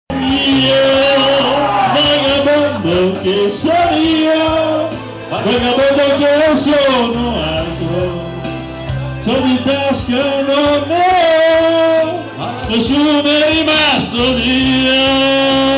Clicka qui per la versione mp3 della nostra hit Clicka qui per la straziante suoneria in MP3